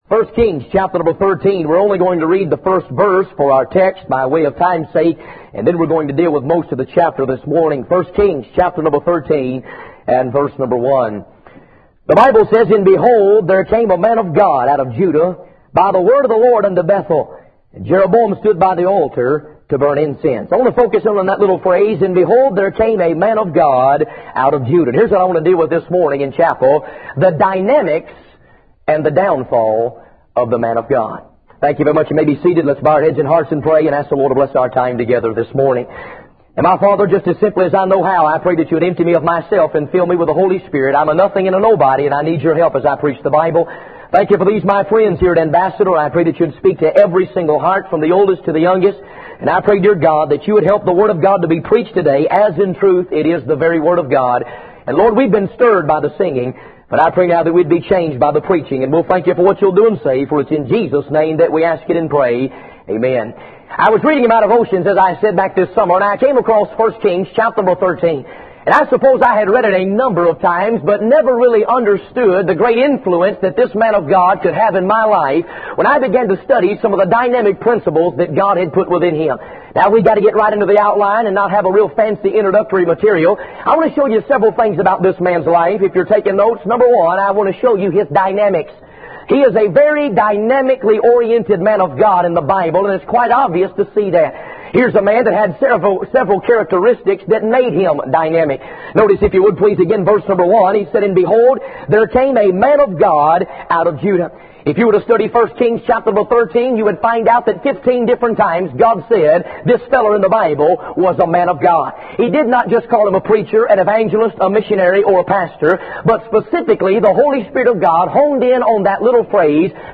In this sermon, the preacher emphasizes the importance of being a fearless and dynamic preacher of the word of God. He criticizes preachers who have lost their fearlessness and focus on positive thinking rather than preaching on sin and hell.